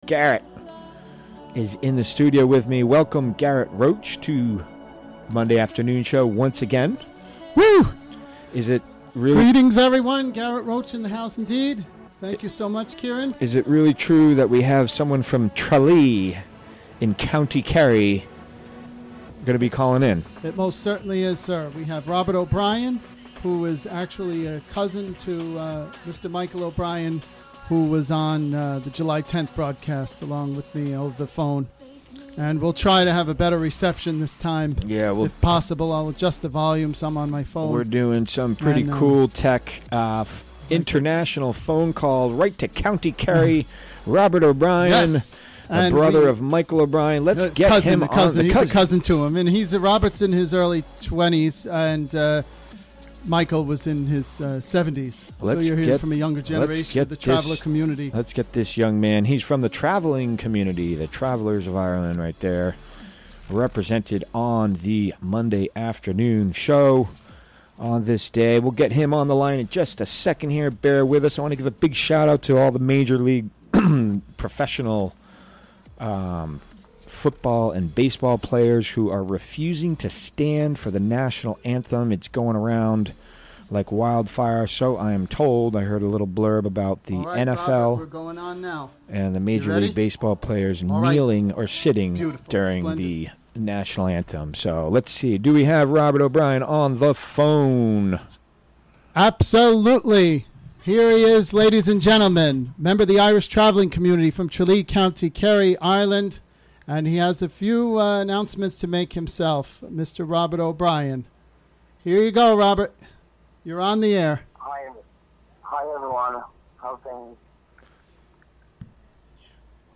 Recorded during the WGXC Afternoon Show Monday, September 25, 2017.